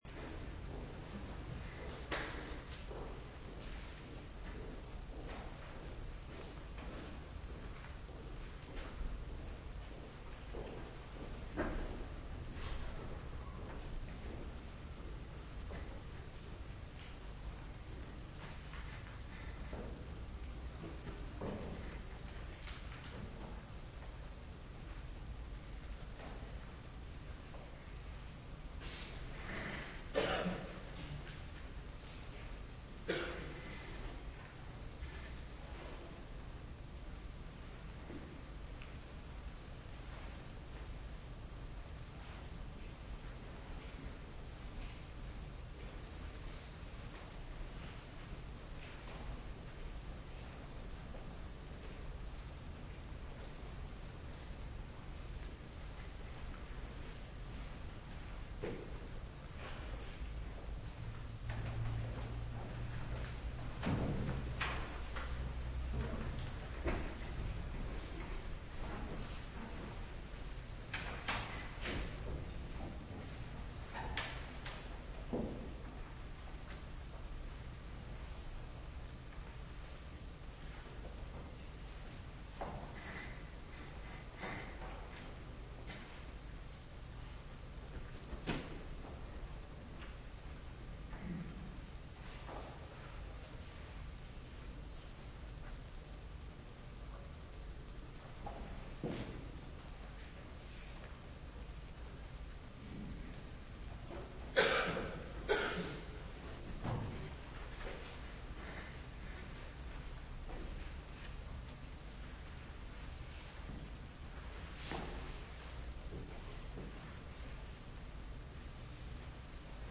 Preek over Openbaring 11 op zondagmorgen 13 maart 2022 - Pauluskerk Gouda